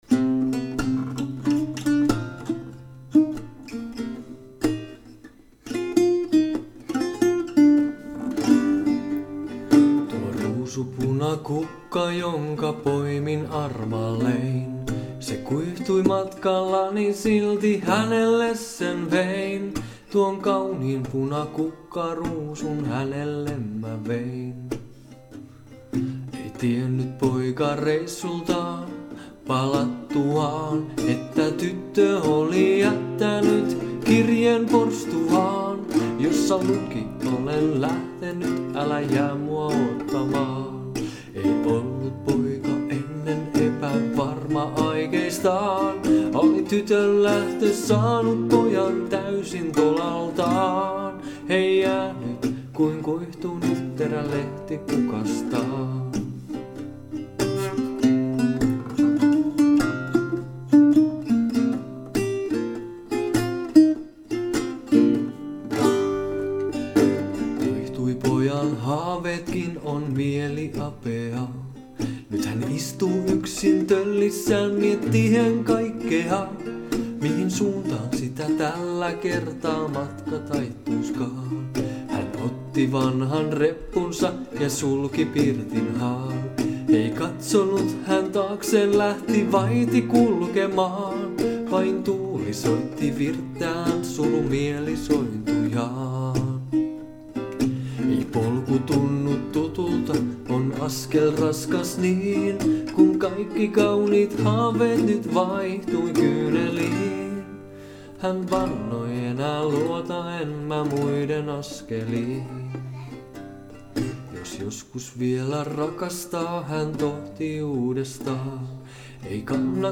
Laulu